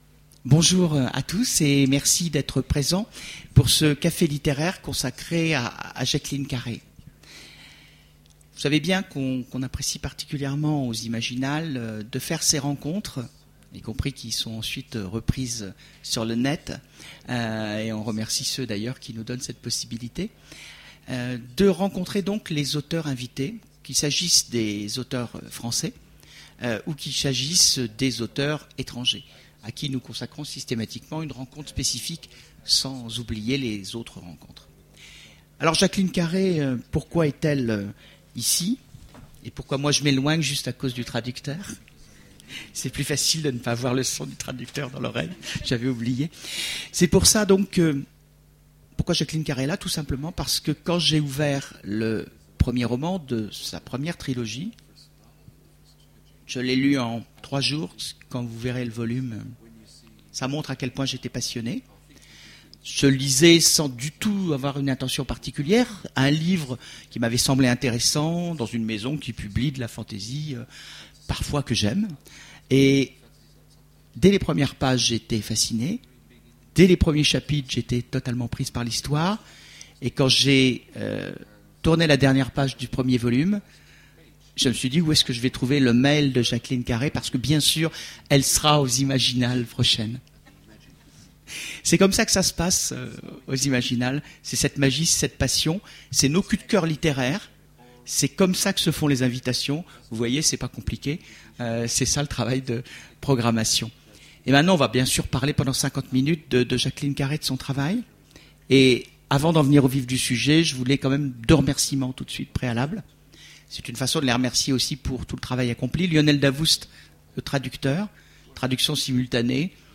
Voici l'enregistrement de la conférence avec Jacqueline Carey aux Imaginales 2010